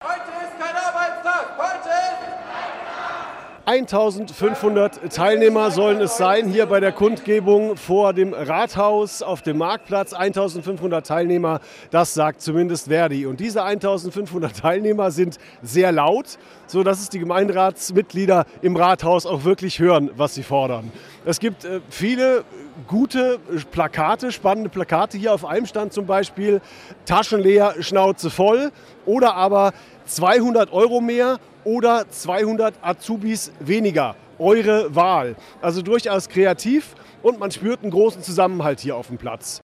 Von dort ging es dann zu einer Kundgebung auf dem Marktplatz.